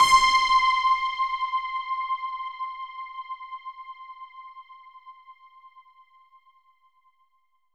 SOUND  C5 -L.wav